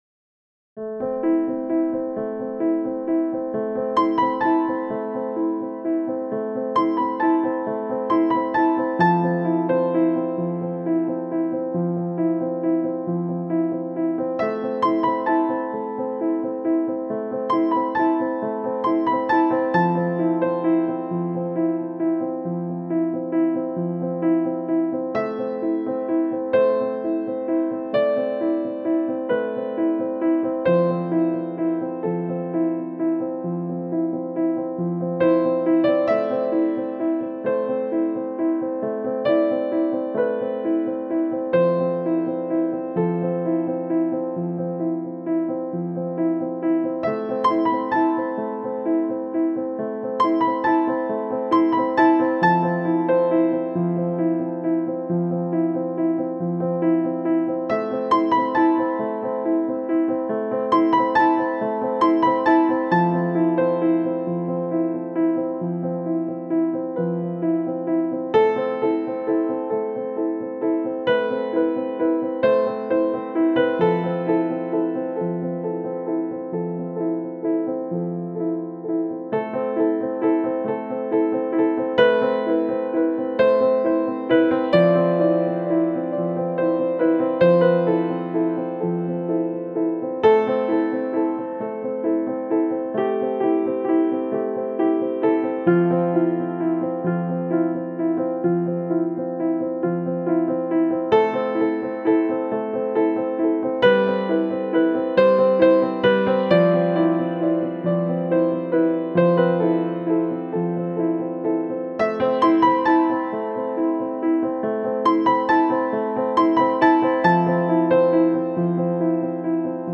The Universe – Piano Theme
Original music for this site specific theatre show that graphically depicts the concentration camp Auschwitz.